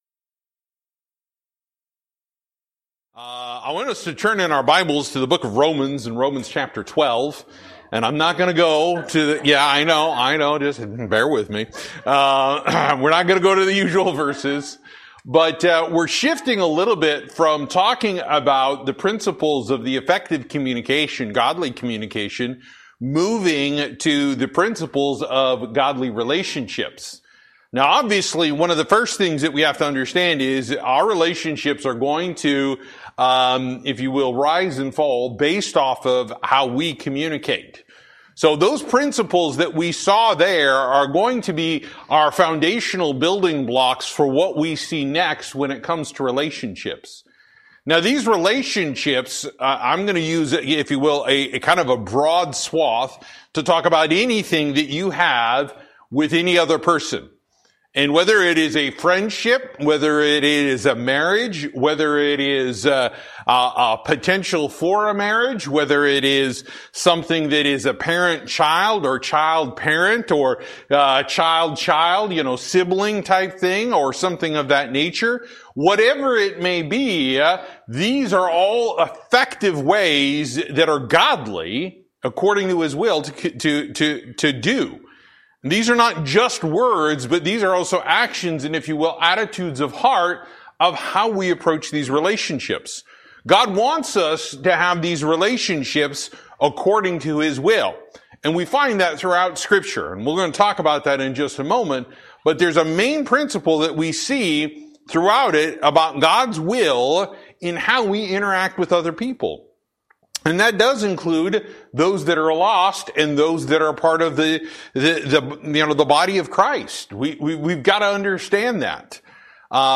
Service: Sunday School